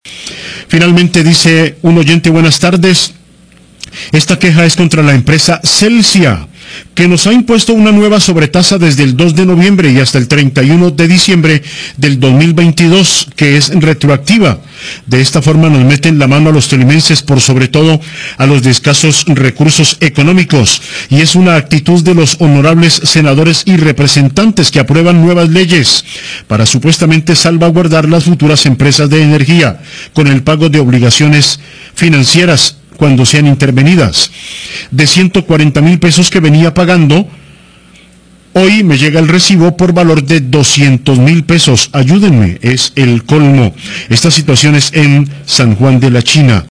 Radio
Oyente del corregimiento San Juan de la China  manifiesta que la nueva sobretasa a la energía está afectado a las clases menos favorecidas del Tolima. Dice que su recibo ha aumentado en un gran porcentaje y espera que la ciudadanía se manifieste en contra de estos elevados costos.